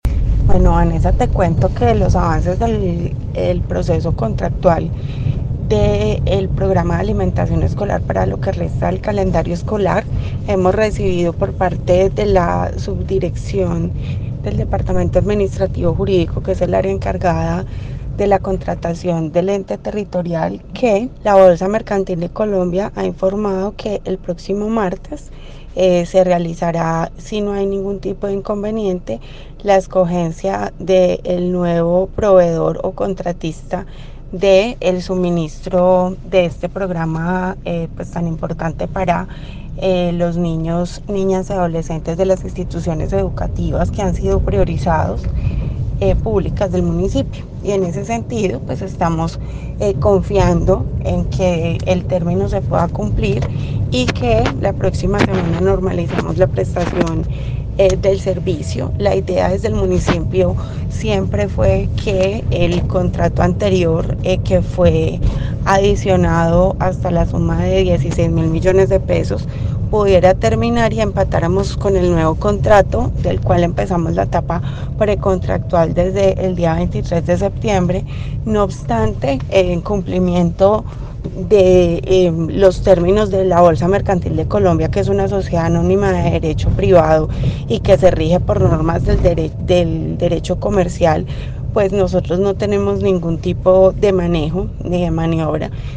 Secretaria de Educación de Armenia